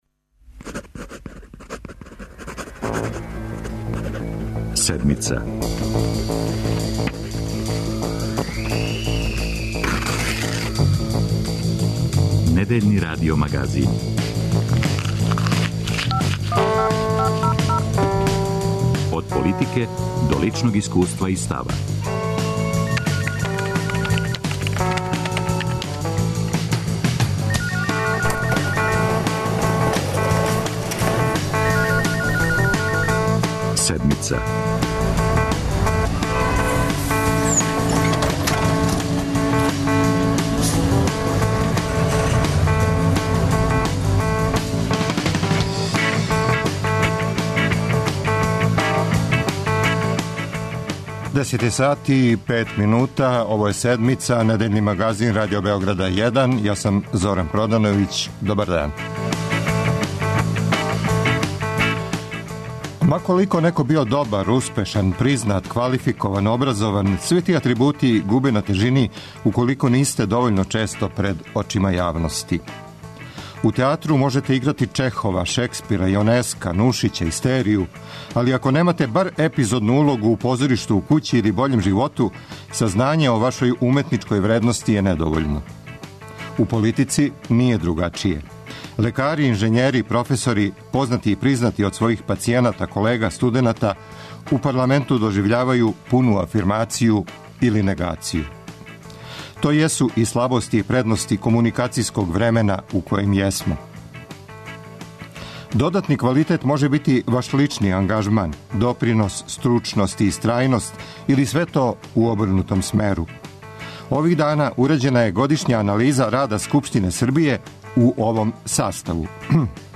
Гост Седмице је Владимир Павићевић.